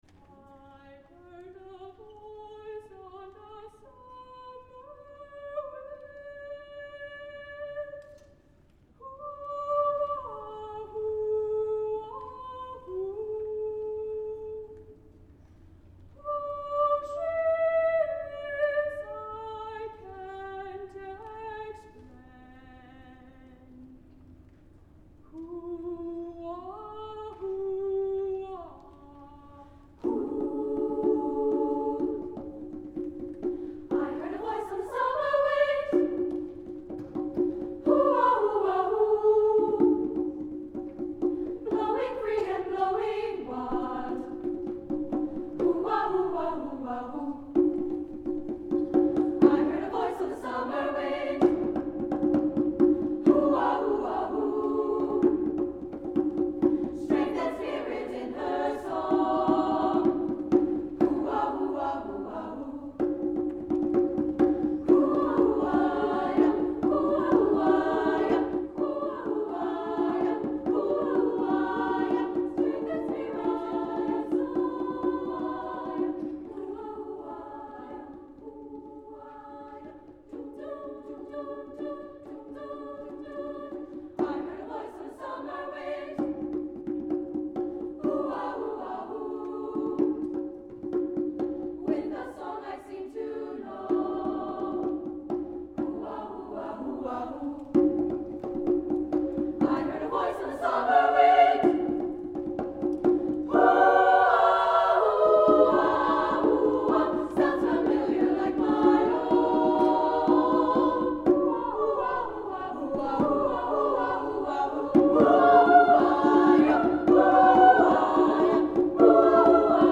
Choral Performance, For Millersville University Students
CANTILENA AT SWEET SOUNDSATIONS CHORAL FESTIVAL, Nov. 3, 2023
cantilena-at-sweet-soundsations-2023-1.mp3